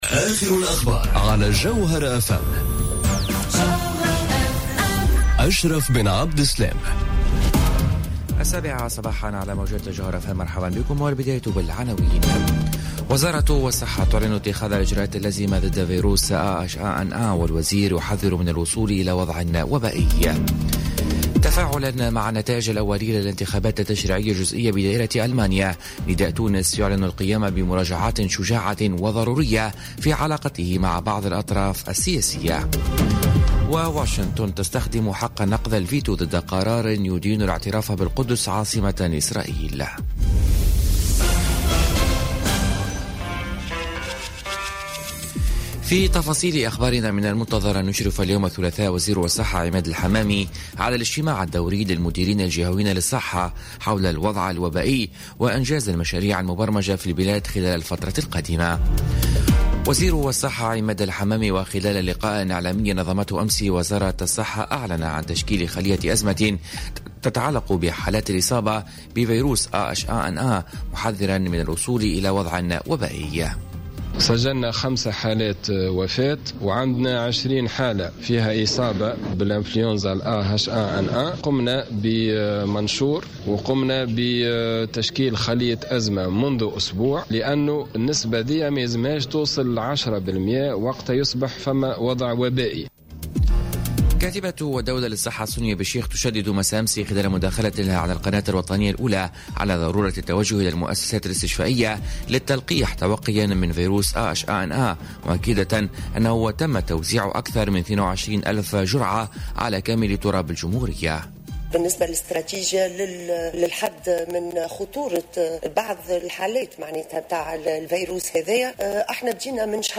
نشرة أخبار السابعة صباحا ليوم الثلاثاء 19 ديسمبر 2018